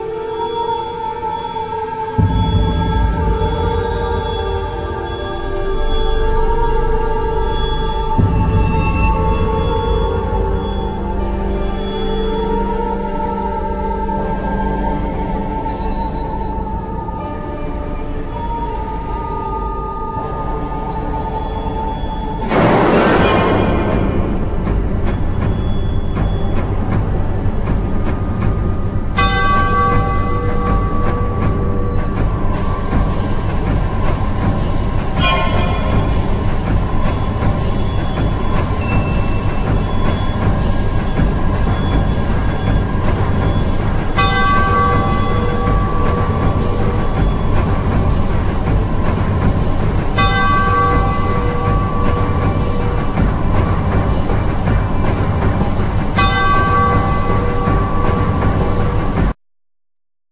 All insturuments, vocals, sound-design, programming
Trumpet, Horn, Trombone
Soprano
Violin, Viola
Violin, Solo violin